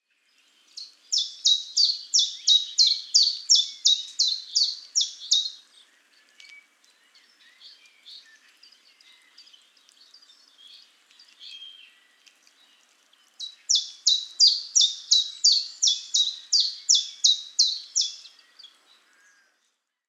Chiffchaff
They have a distinctive song and essentially sing their name ‘chiff chaff chiff chaff’ but some individuals can sometimes get confused and sing ‘chaff chiff chaff chiff’…